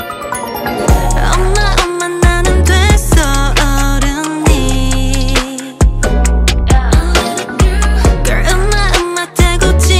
LSL_130_latin_perc_loop_marvin_top